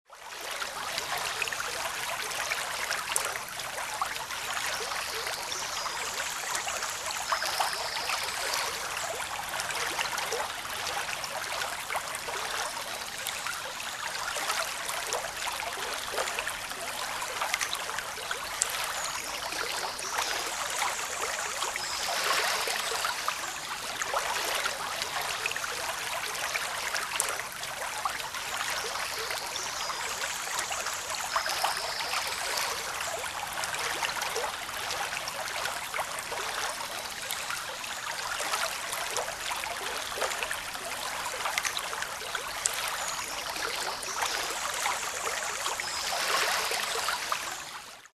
Genre: Noise.